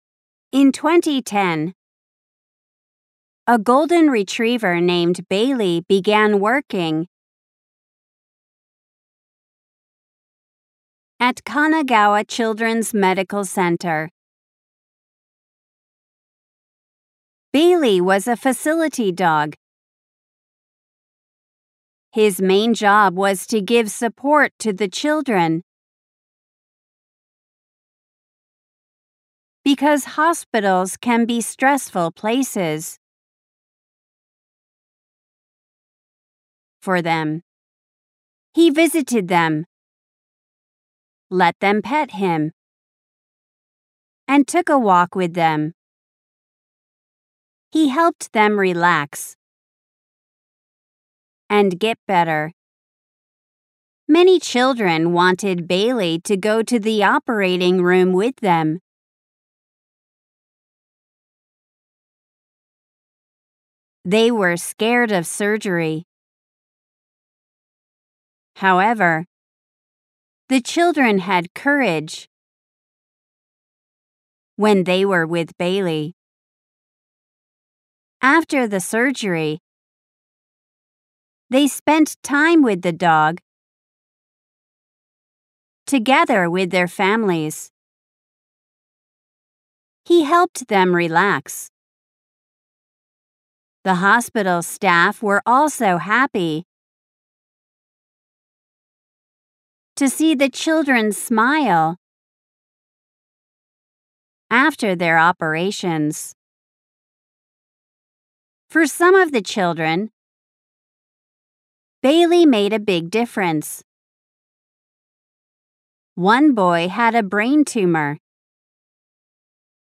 本文音声
「ポーズ付き」・・・本文をフレーズごとに区切り，リピートできるポーズを入れた音声です。